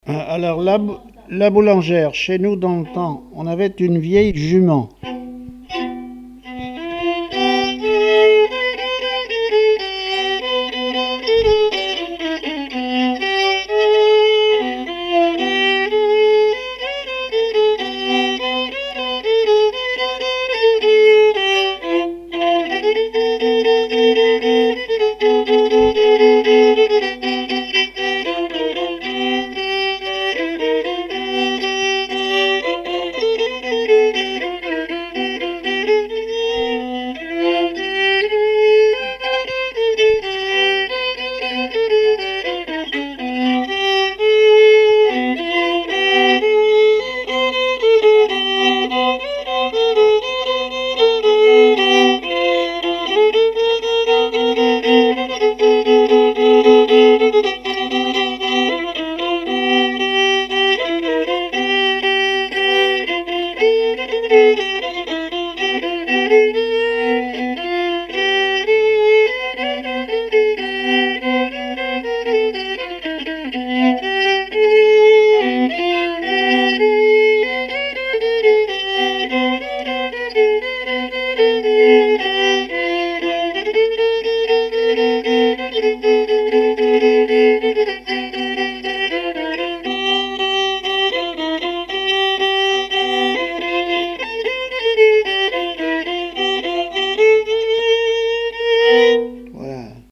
danse : polka boulangère
répertoire musical au violon
Pièce musicale inédite